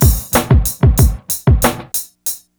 BEAT 4 92.03.wav